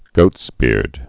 (gōtsbîrd)